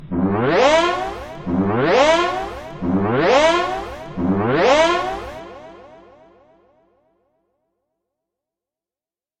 SFX音效 " 时髦闹钟05
描述：时髦的警报 使用Audacity创建
标签： 迷幻 转换器 梦幻 神秘的 怪异的 手机 电池 警报 振动 如梦如幻 报警 铁三角 巨大
声道立体声